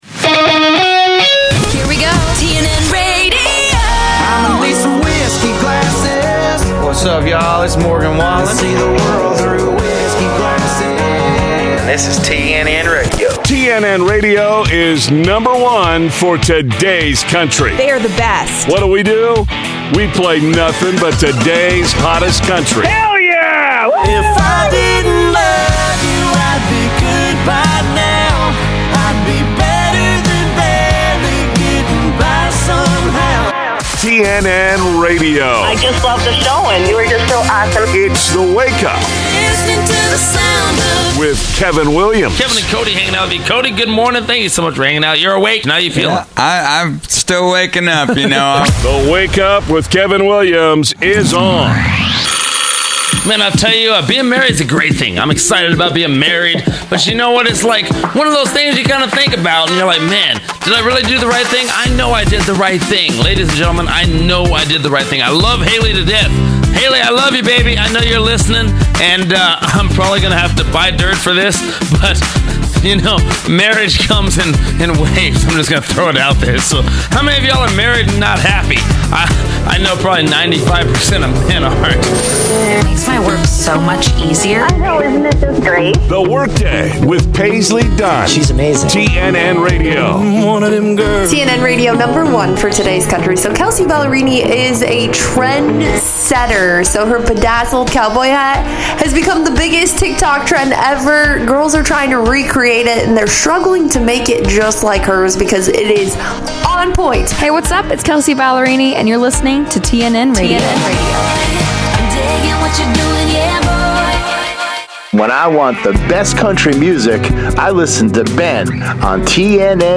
TNN Radio plays the best mix of mainstream country, primarily from 2015-present, but pride